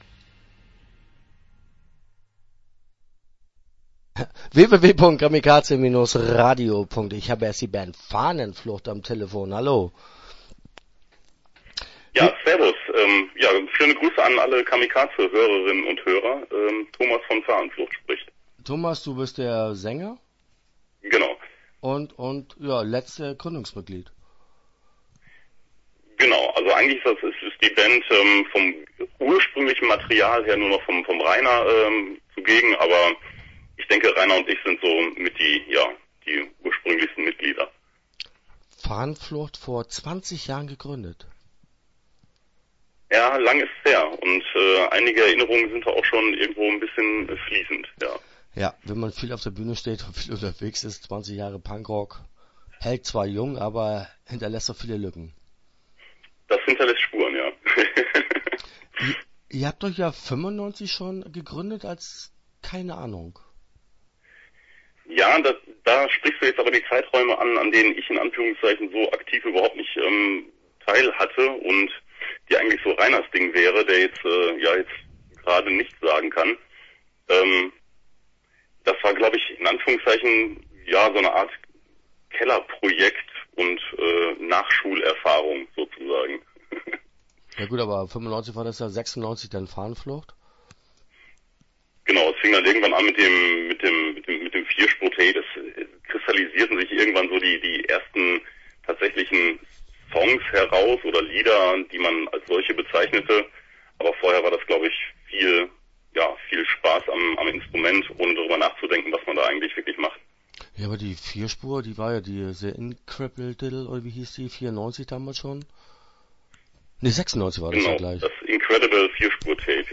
Start » Interviews » Fahnenflucht